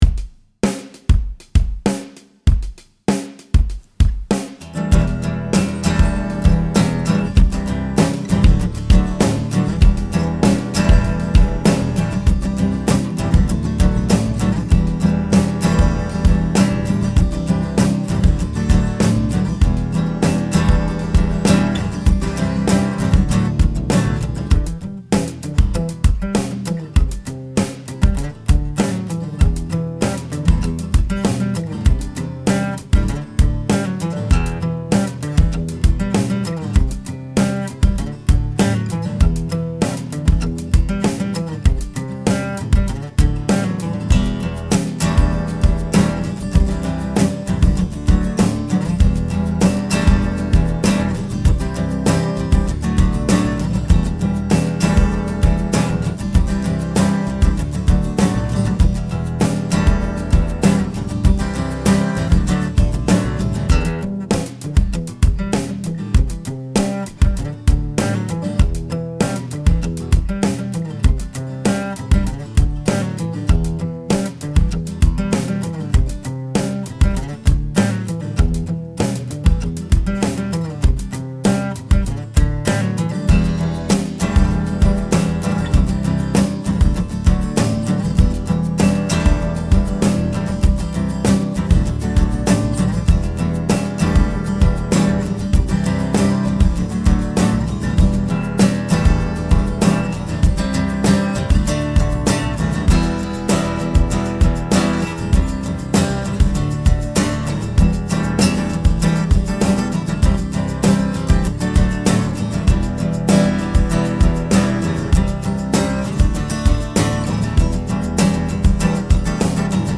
All-Of-Us-Instrumental-backing-track-.mp3